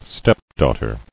[step·daugh·ter]